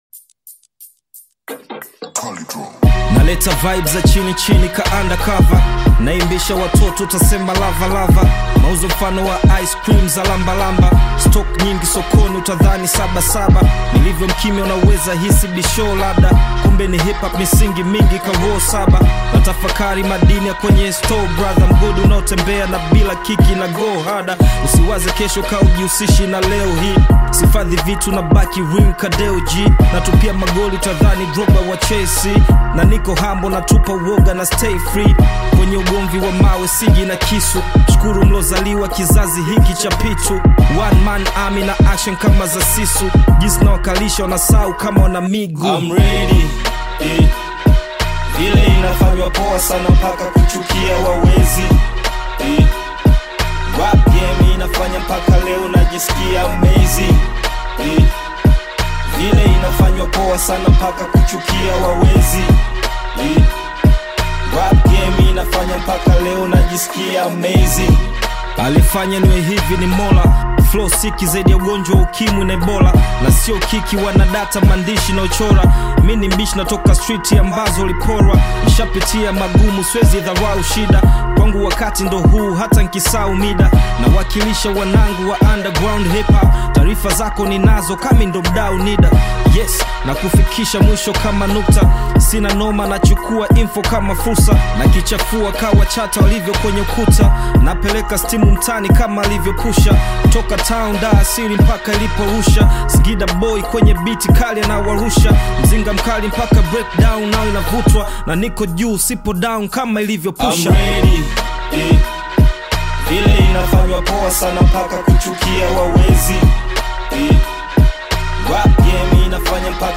HIP HOP Music